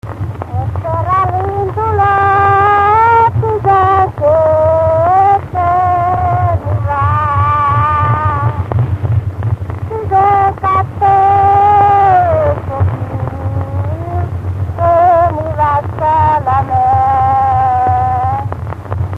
Erdély - Csík vm. - Csíkverebes
ének
Műfaj: Ballada
Stílus: 7. Régies kisambitusú dallamok
Szótagszám: 6.6.6.6
Kadencia: 5 (2) 4 1